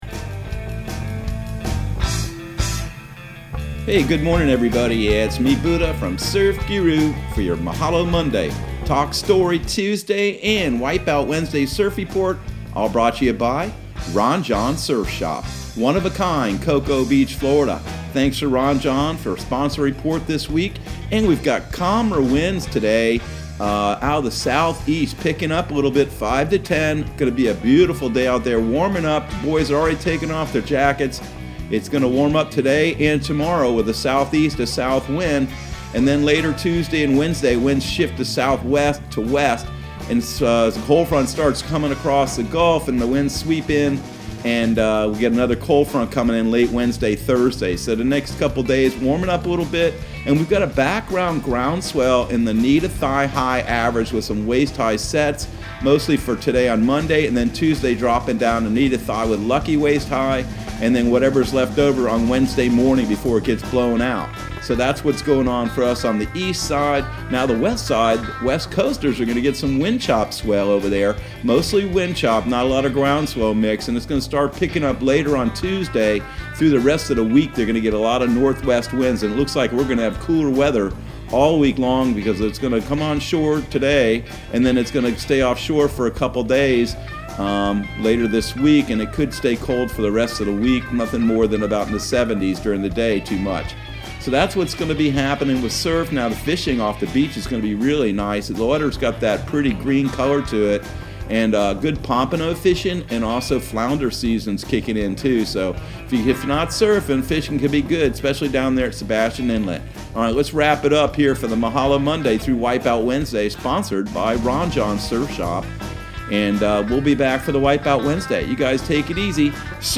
Surf Guru Surf Report and Forecast 01/11/2021 Audio surf report and surf forecast on January 11 for Central Florida and the Southeast.